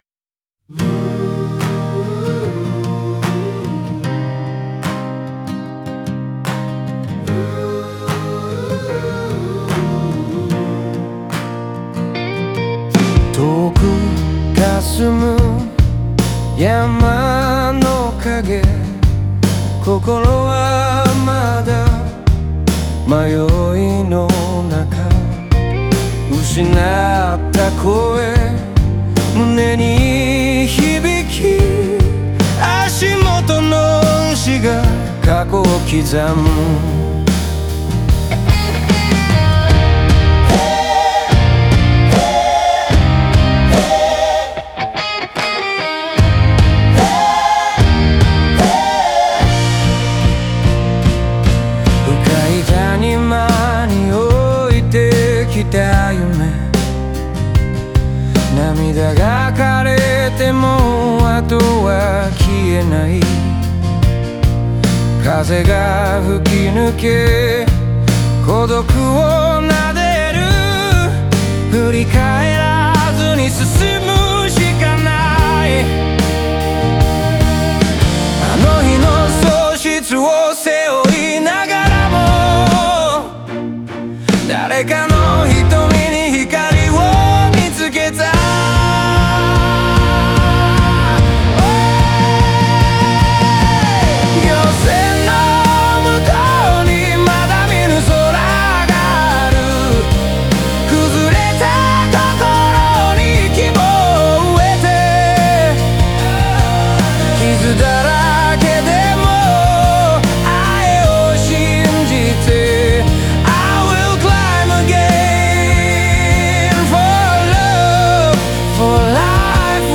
日本語の情緒的な表現と英語の力強いフレーズを織り交ぜることで、内面的な葛藤と解放感が国際的な響きで表現されています。